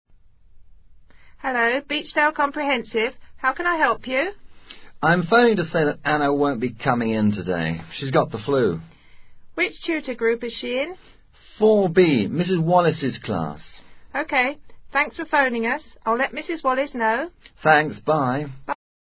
Breve diálogo que simula una conversación telefónica en la que un padre informa a una recepcionista que su hija no asistirá a clases.